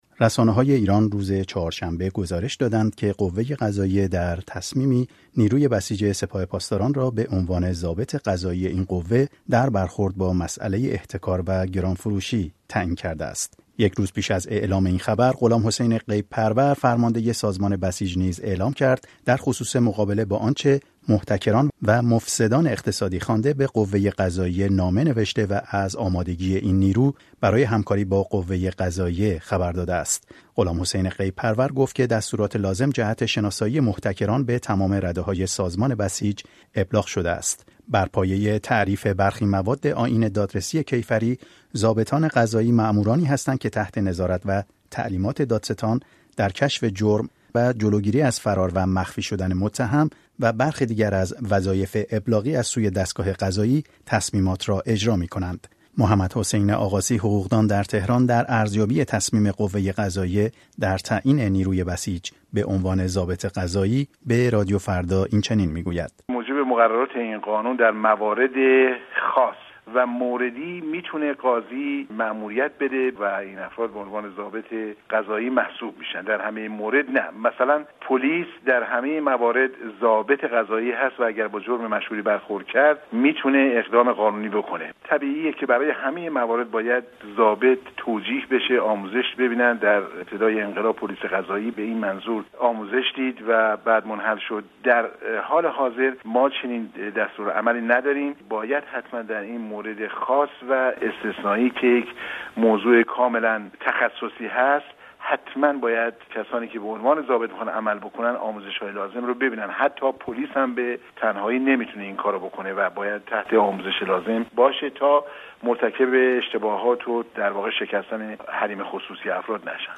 با تصمیم قوه قضائیه ایران، نیروی بسیج سپاه پاسداران به عنوان ضابط قضایی این قوه در برخورد با مسئله «احتکار و مفاسد اقتصادی» تعیین شد. این اقدامات در حالی است که حسن روحانی، رئیس‌جمهوری ایران، خواستار رعایت قانون در برخورد با «مفاسد اقتصادی» شده است. گزارش رادیویی